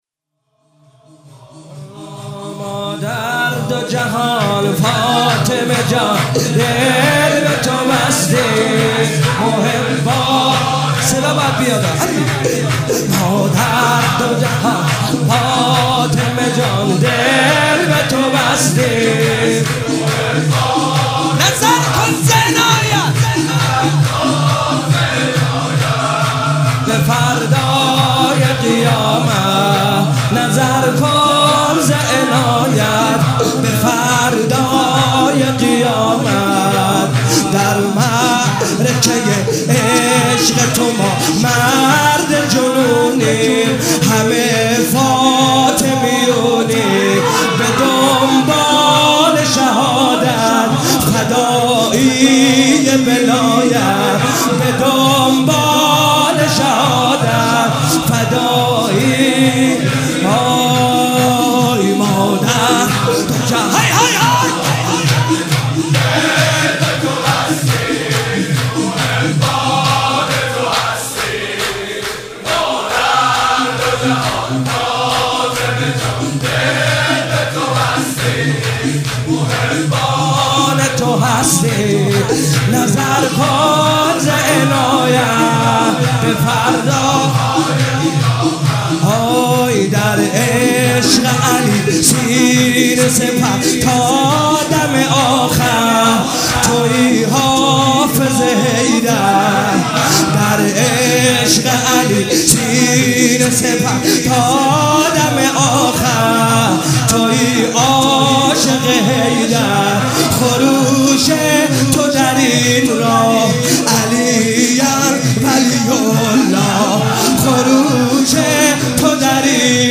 شور - ما در دو جهان فاطمه جان